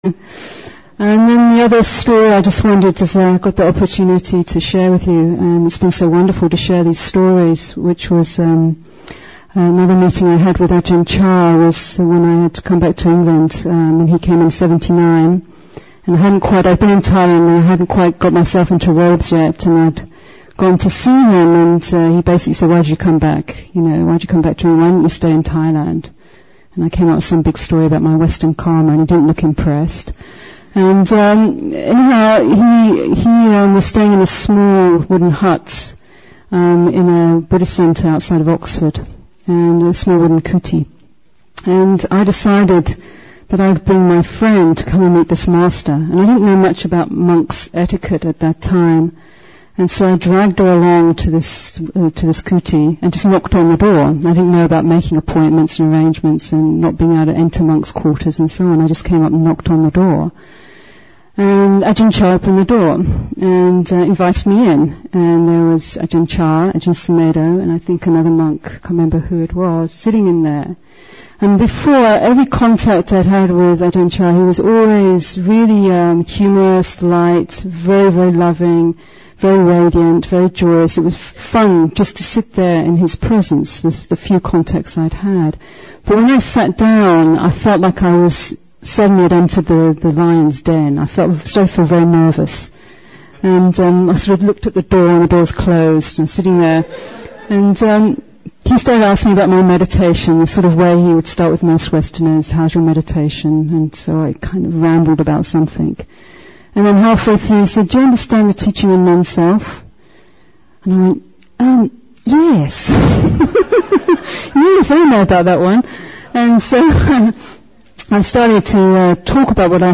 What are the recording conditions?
Remembering Ajahn Chah Weekend, Session 32 – Apr. 29, 2001